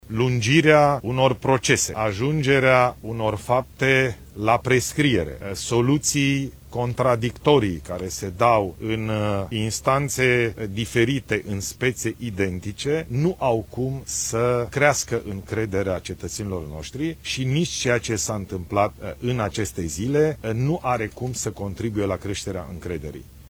Ilie Bolojan: „Ce s-a întâmplat în aceste zile nu are cum să contribuie la creșterea încrederii”